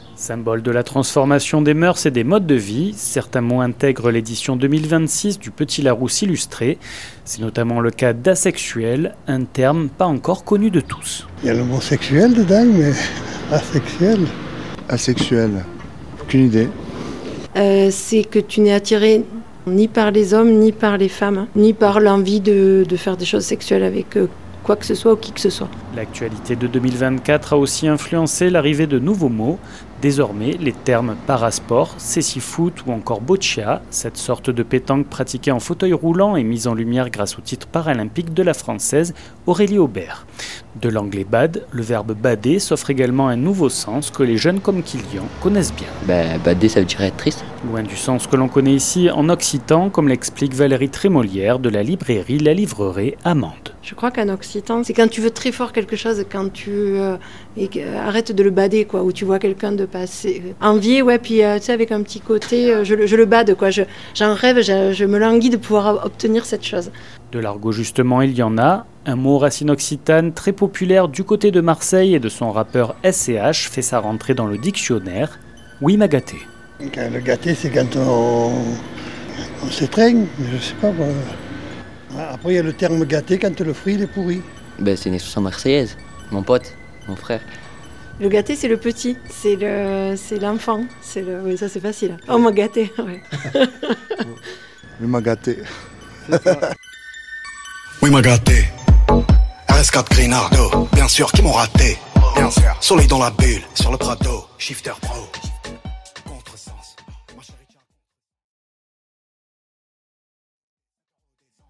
On est allé le vérifier dans les rues de Mende.
Reportage